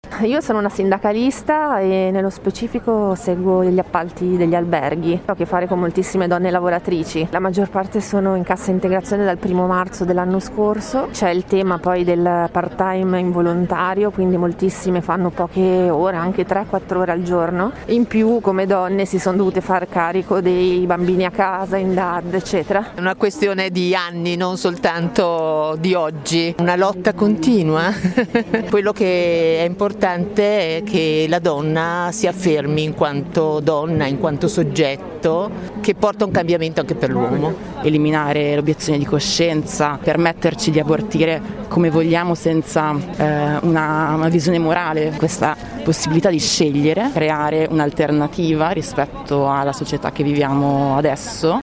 voci-19.30-ottomarzo-manif-milano.mp3